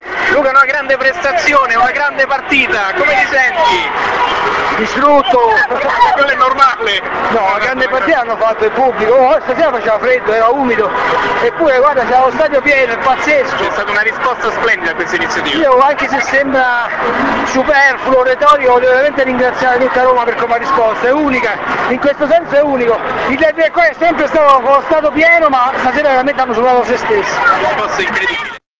Speciale Derby del Cuore - INTERVISTE AI PERSONAGGI